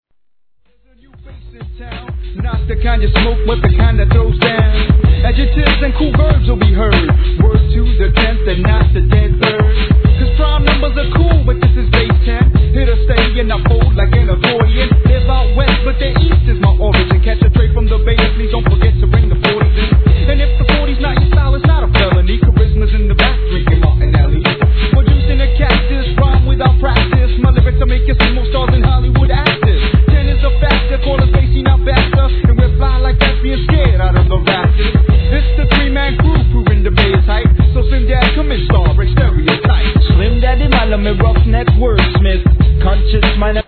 1. HIP HOP/R&B
センスあるネタ使いのDOPEトラックで人気の'90sマイナー盤!!